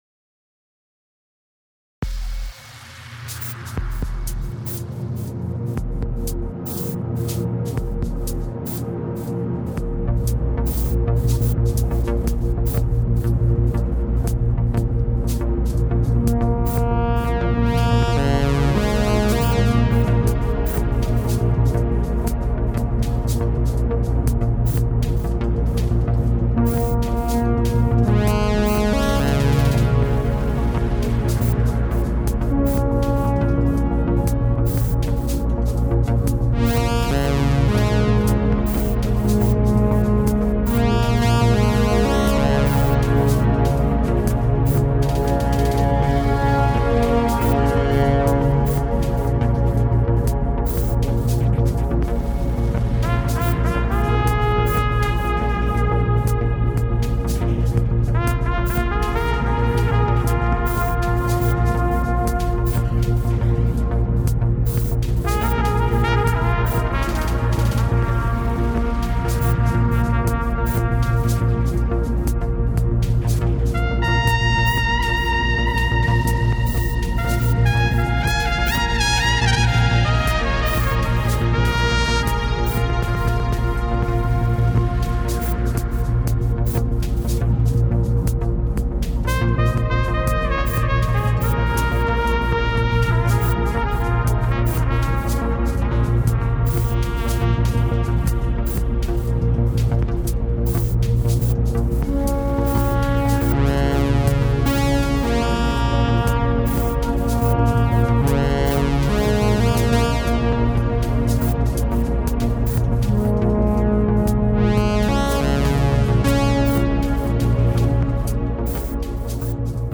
Сколько может стоить в России(примерно) изготовление видео для электронной музыки (трек на 5 мин.)